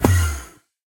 Minecraft Version Minecraft Version snapshot Latest Release | Latest Snapshot snapshot / assets / minecraft / sounds / mob / endermen / portal.ogg Compare With Compare With Latest Release | Latest Snapshot
portal.ogg